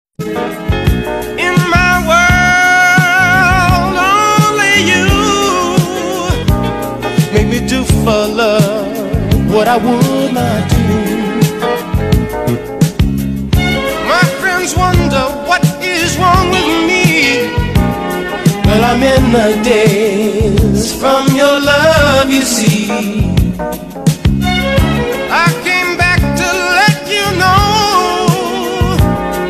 This man sang this song incredibly well.